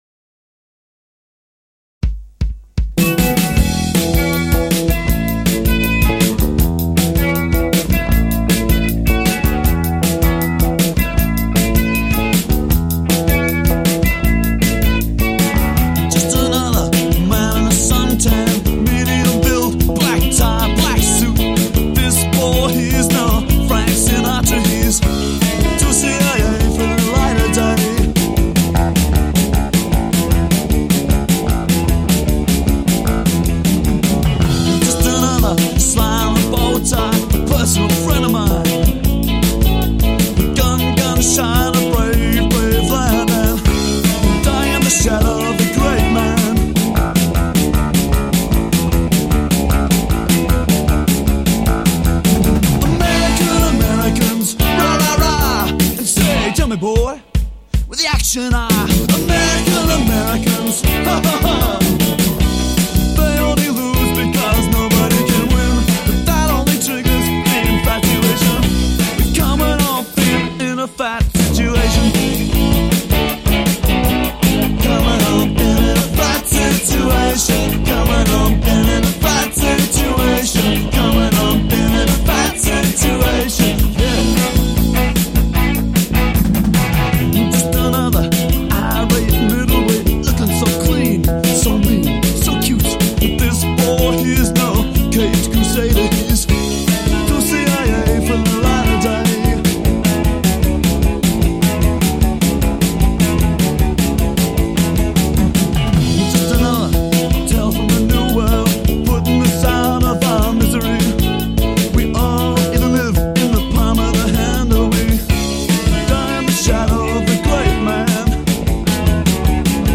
bass
drums
lead vocals/guitar
These two songs also feature guest backing vocals